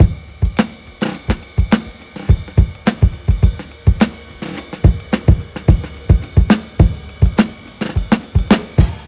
• 106 Bpm Drum Beat G Key.wav
Free drum groove - kick tuned to the G note. Loudest frequency: 843Hz
106-bpm-drum-beat-g-key-fqt.wav